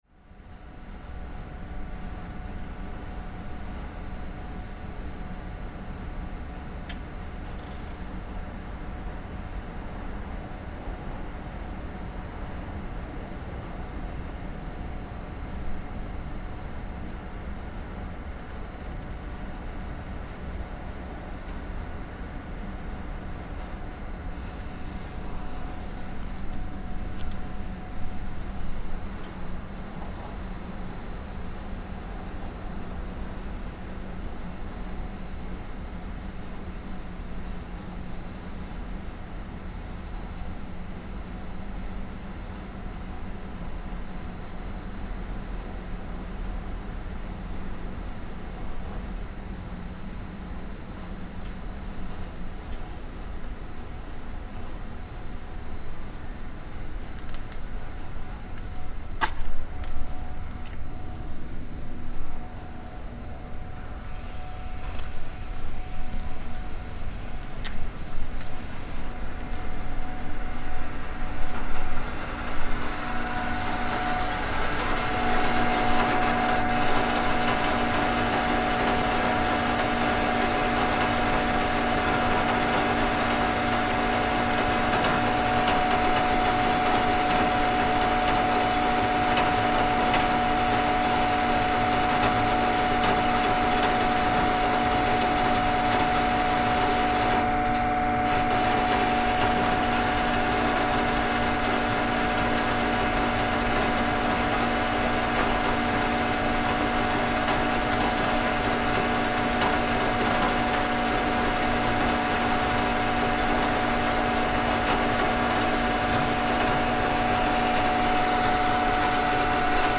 Outside the Department of Earth Sciences at the University of Oxford, there are several gigantic, almost-building height tanks of liquid nitrogen behind a fence, encrusted with ice and with vapour hissing out from the pipes at the bottom.
The sounds of hissing liquid nitrogen vapours mix with the industrial drones of other nearby scientific machinery and air conditioning devices coming from science labs, a sonic reminder of all the fantastic research taking place behind closed doors in these advanced university research facilities.